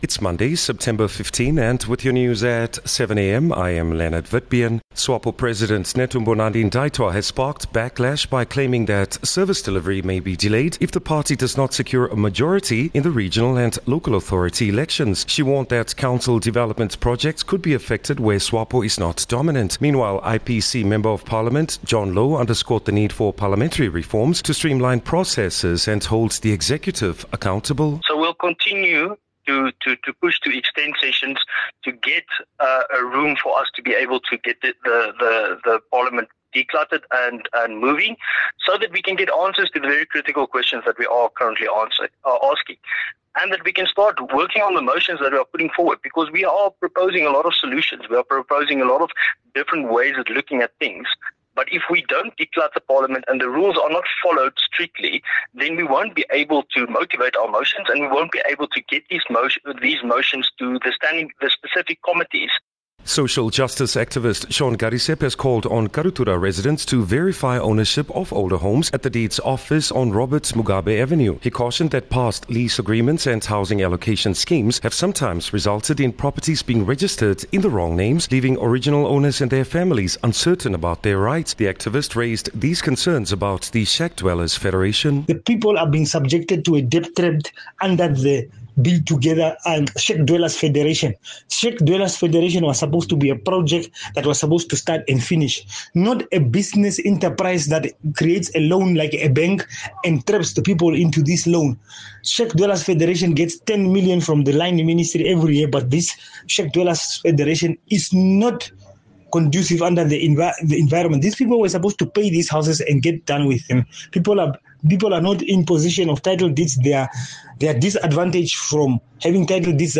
15 Sep 15 September-7am news